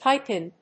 pipe+in.mp3